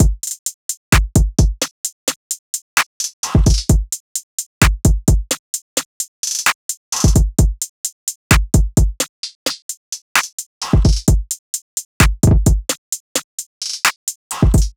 SOUTHSIDE_beat_loop_cut_full_02_130.wav